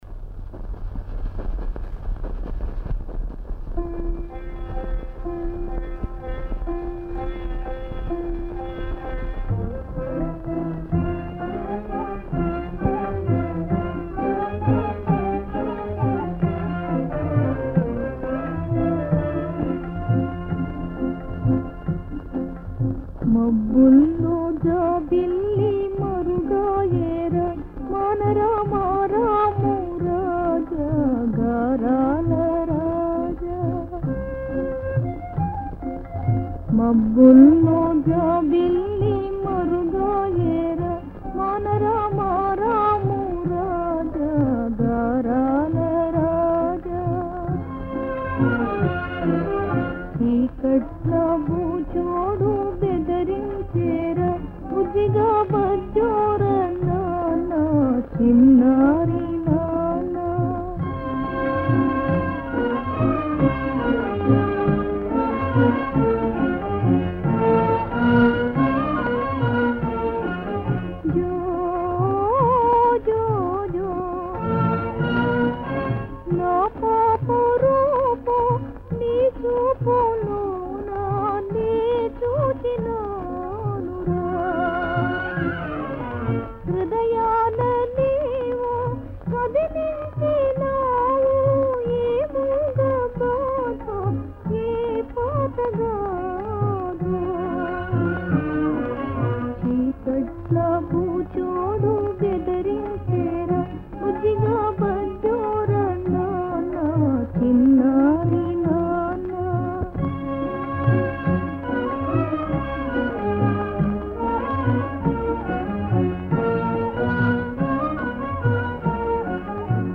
రెండు గ్రామఫోను రికార్డులు మాత్రం విడుదలయ్యాయి.